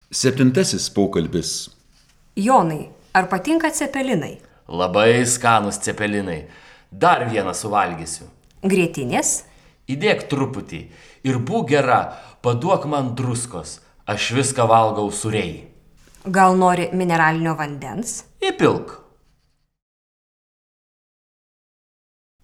04_Dialog_7.wav